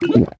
drink_potion.ogg